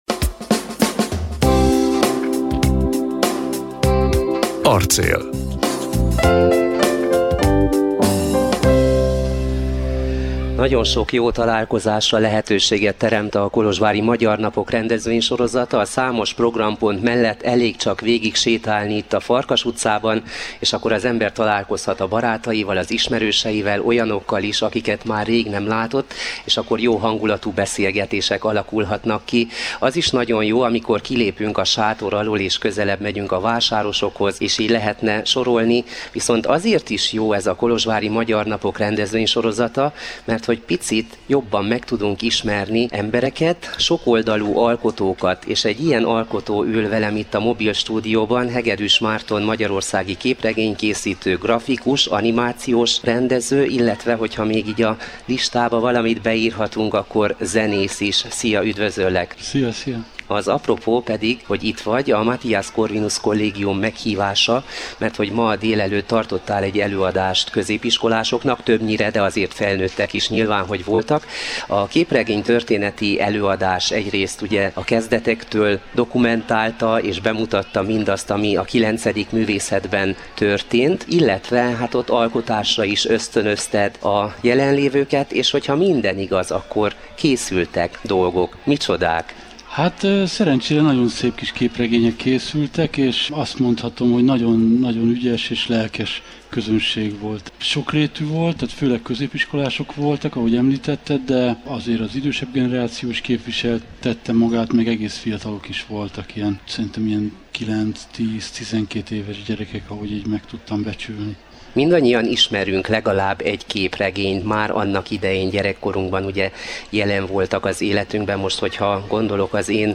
A magyarországi képregénykészítő, grafikus, animációs rendező kihelyezett stúdiónkban járt.